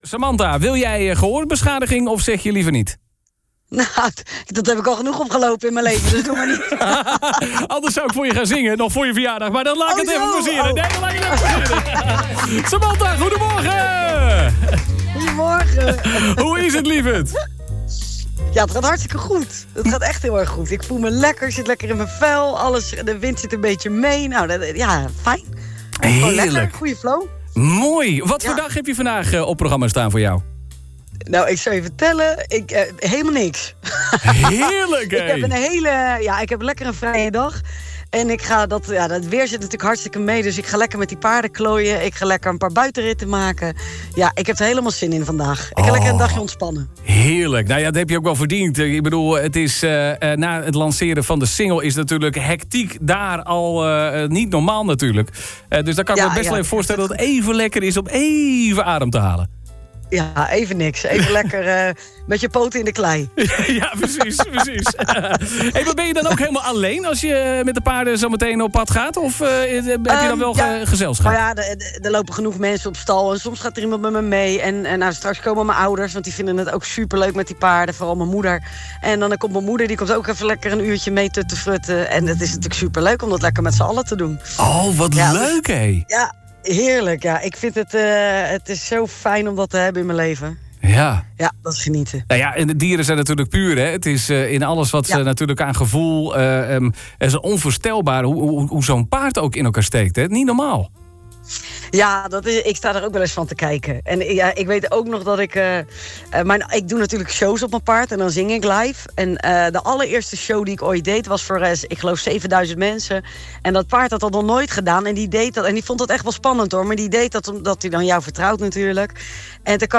BELLEN MET: SAMANTHA STEENWIJK (AUDIO)
Deze ochtend hadden we de altijd goedlachse Samantha Steenwijk aan de telefoon over onder andere haar nieuwe single.